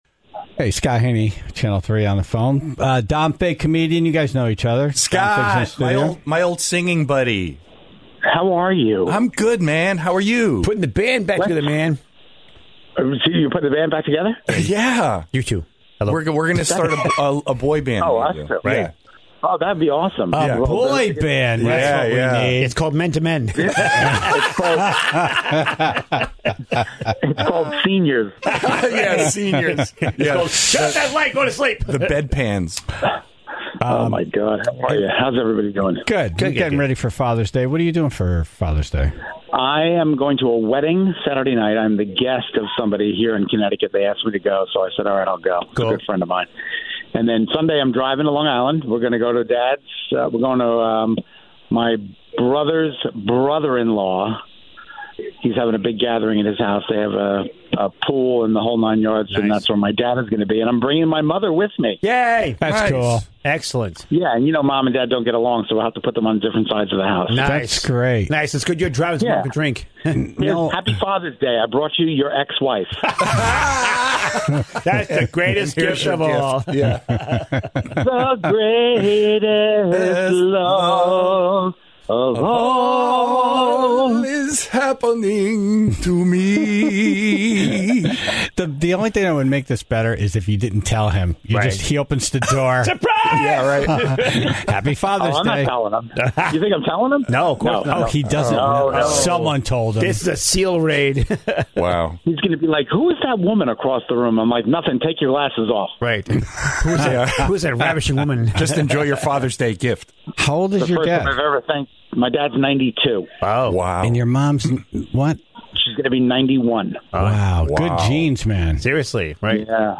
All the fails from a week of broadcasting.